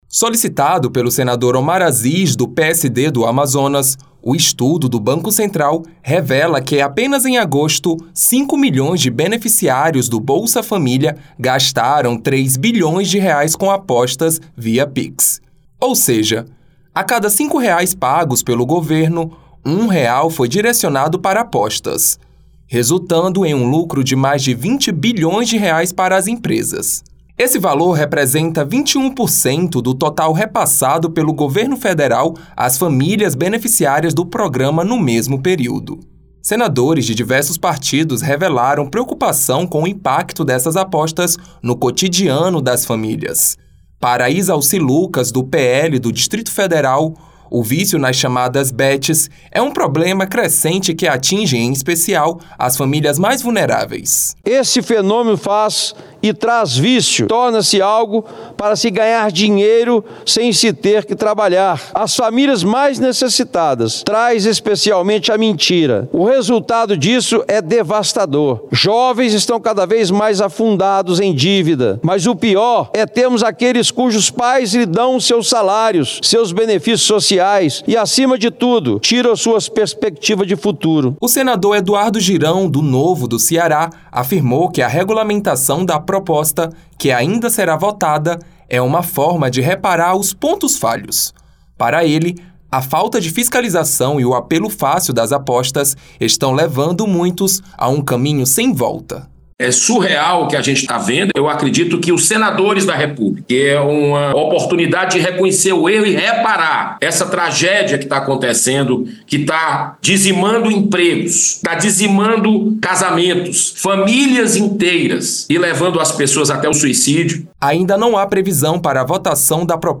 Os parlamentares alertaram para o crescimento do vício em jogos e para o uso de benefícios sociais no pagamento das apostas. O senador Izalci Lucas (PL-DF) criticou a falta de controle do governo e destacou que a regulamentação em tramitação no Senado ainda não oferece soluções eficazes.